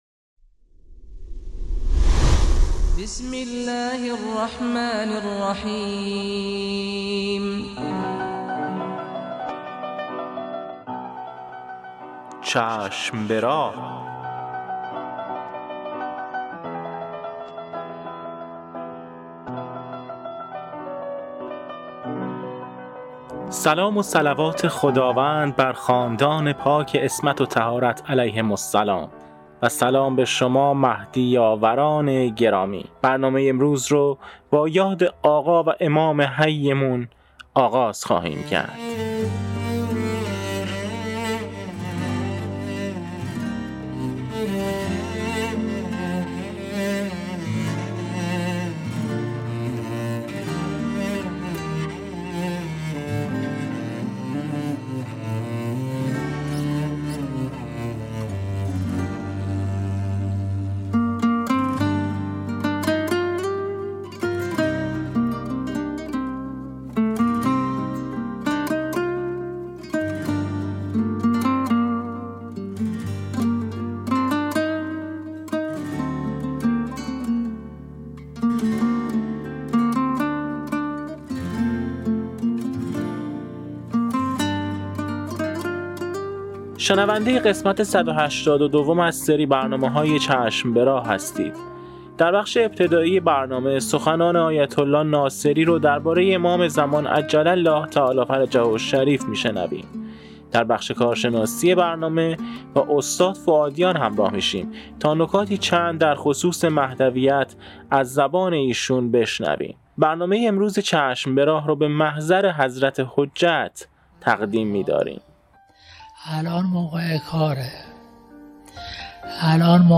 قسمت صد و هشتاد و دوم مجله رادیویی چشم به راه که با همت روابط عمومی بنیاد فرهنگی حضرت مهدی موعود(عج) تهیه و تولید شده است، منتشر شد.